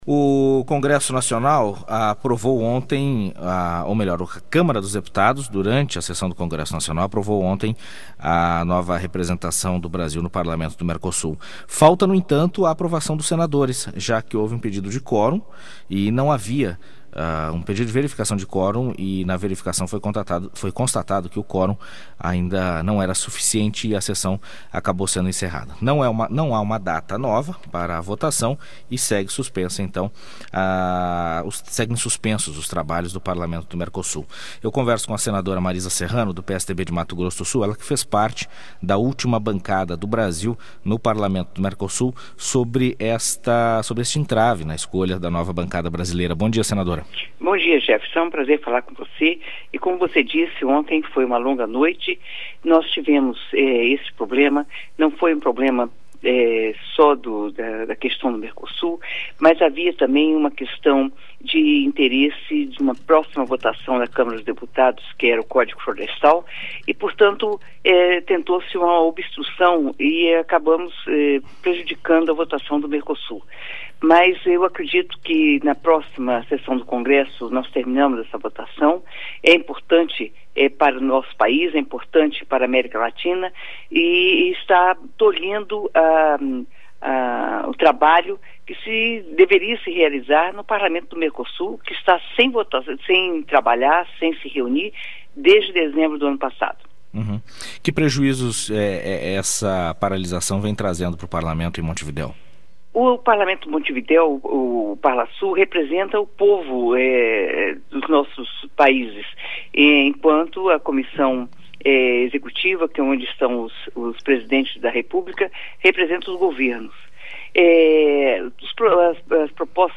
Entrevista com a senadora Marisa Serrano (PSDB-MS).